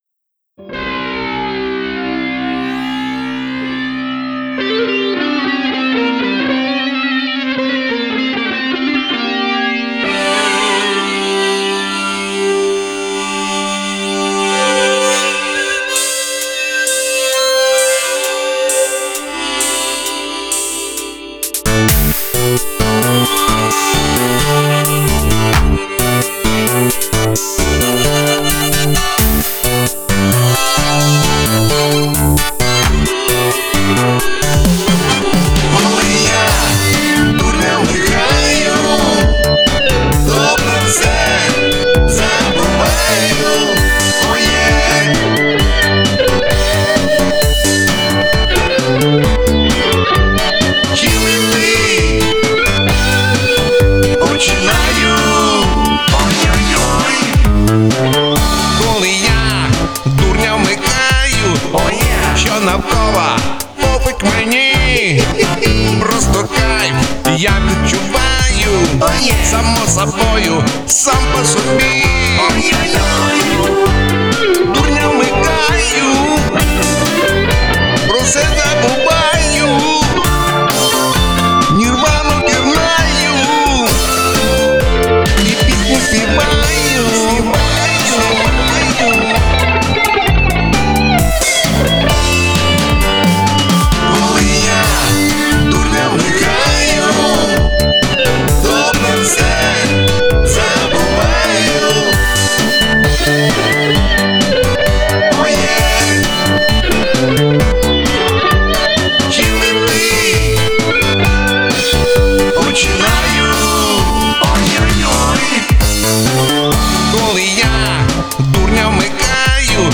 Стиль: Рок
гітара і губна гармошка